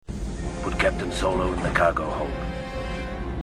Cytaty z Imperium Kontratakuje są w dwóch wersjach, JW to Jason Wingreen, czyli głos "Stary", a TM to Temuera Morrison, czyli głos "Nowy".
*** (TM)Boba wydaj±cy polecenie.